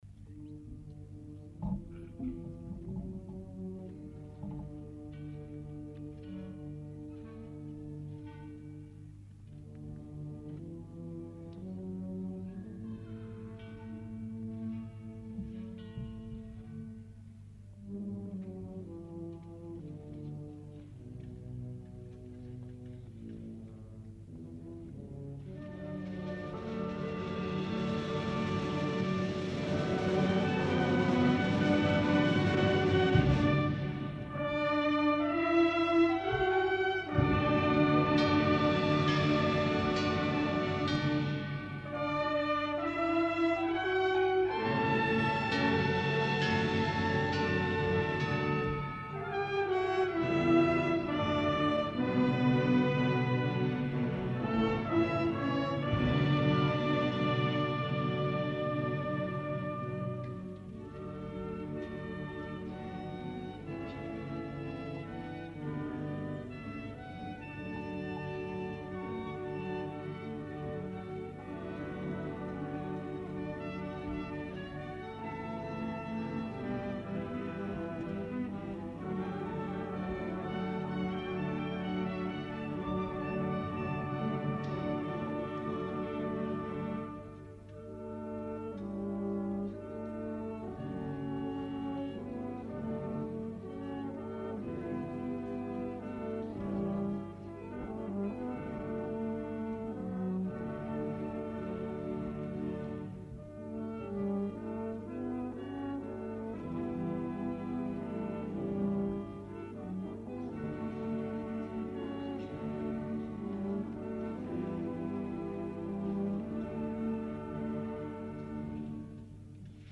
Concert de Santa Cecília a l'Esglèsia de la Nostra Senyora de la Consolació